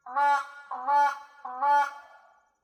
Guard Call | A sharp, single call expressing alarm.
Black-Crowned-Crane-Alarm.mp3